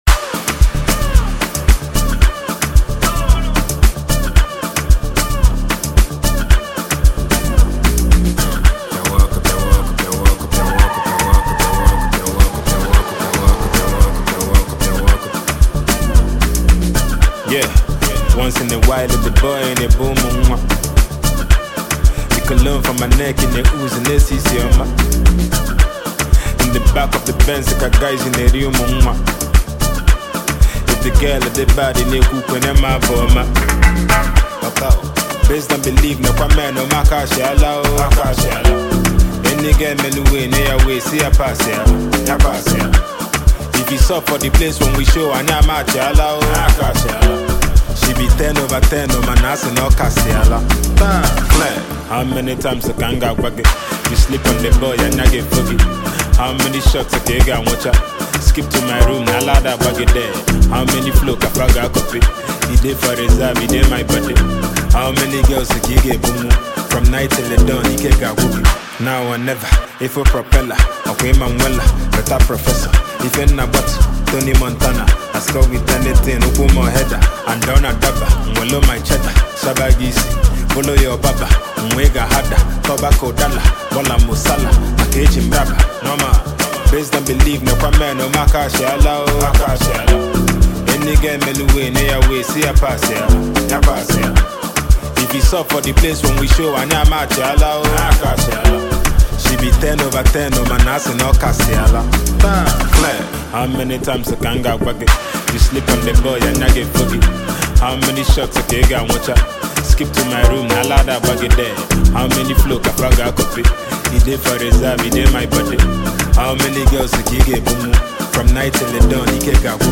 Banging tune